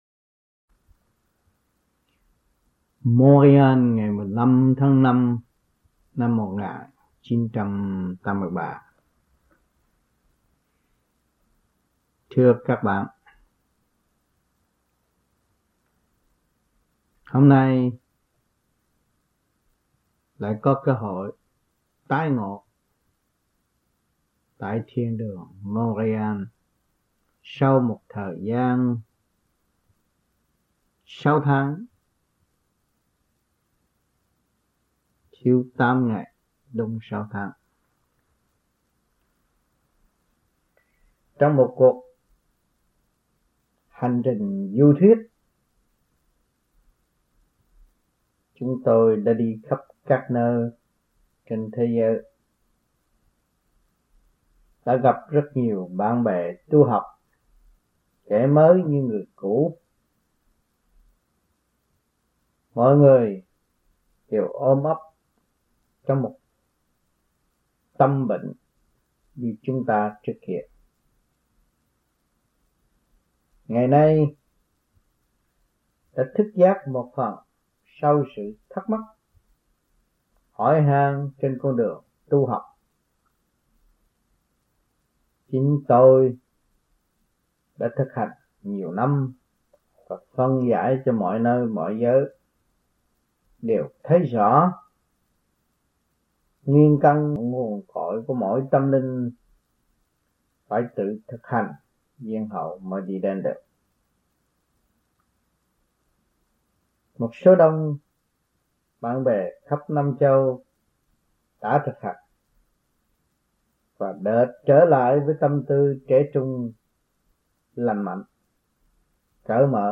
Băng Giảng